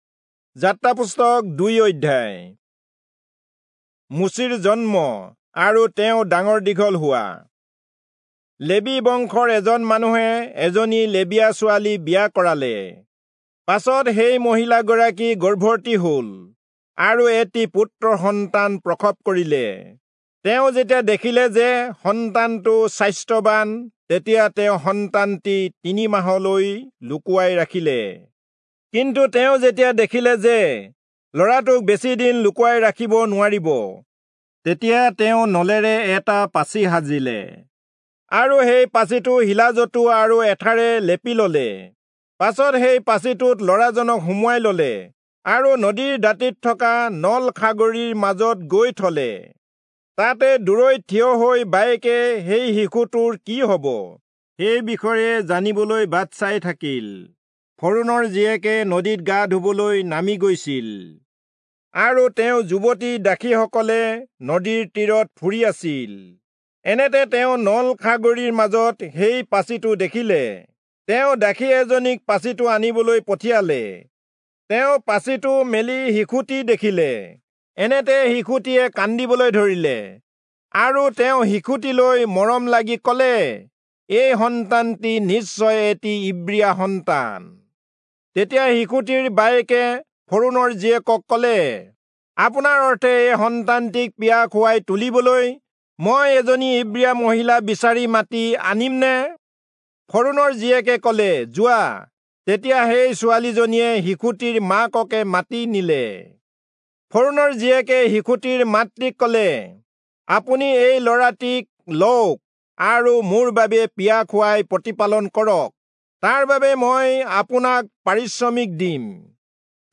Assamese Audio Bible - Exodus 10 in Tev bible version